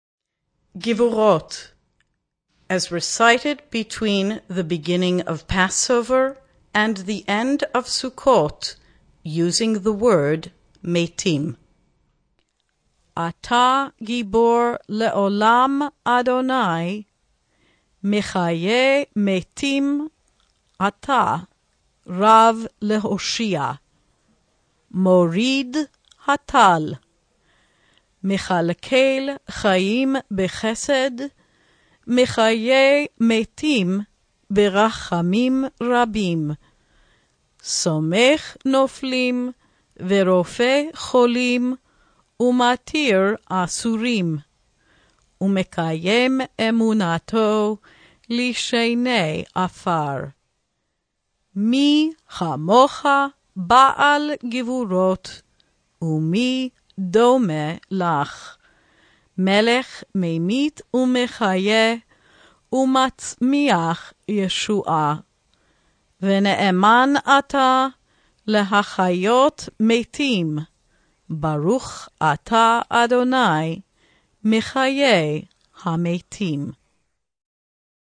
R12 Gvurot Summer Meitim Read.mp3